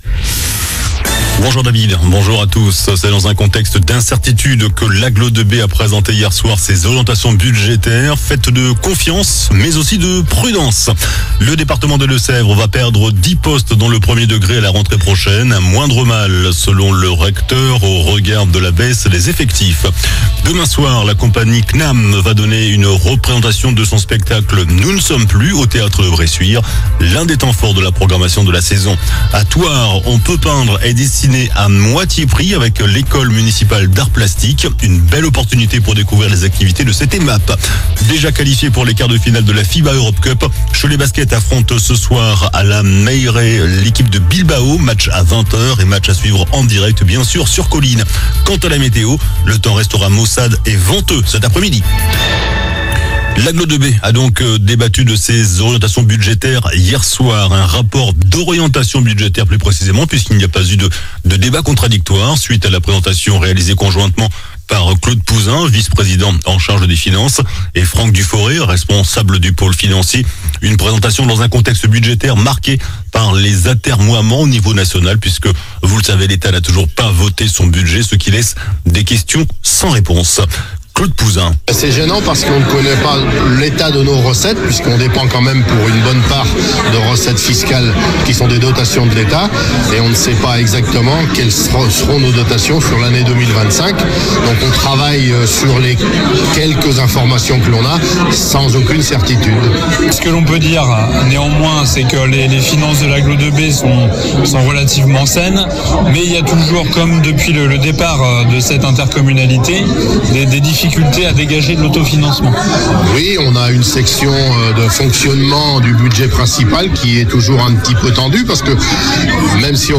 JOURNAL DU 29 JANVIER ( MIDI )